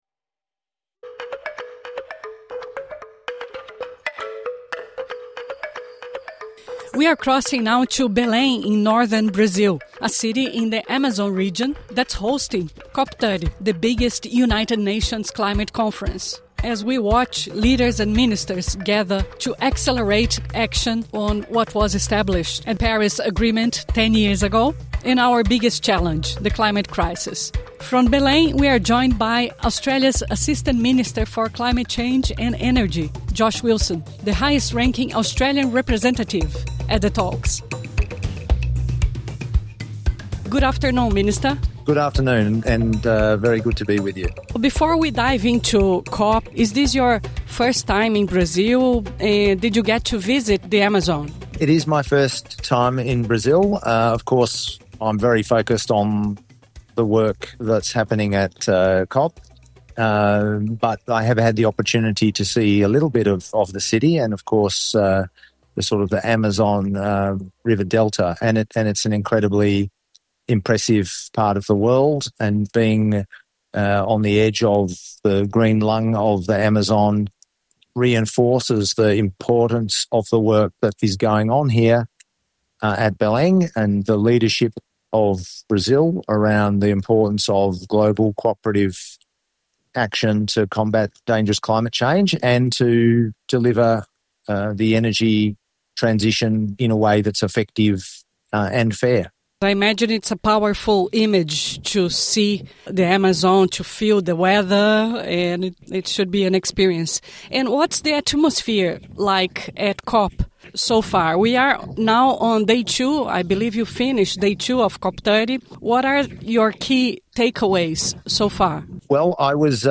SBS Portuguese spoke to Assistant Minister for Climate Change and Energy, Josh Wilson, Australia’s highest-ranking official at COP30 in Belém, Brazil. AM Wilson spoke about Australia’s bid to host COP31, the balance between Australia’s fossil fuel exports and renewable energy ambitions, the absence of Prime Minister Anthony Albanese at COP, how a COP can achieve results without USA and China, and his reaction to today’s Liberal Party meeting to debate (and dump) net-zero emissions target.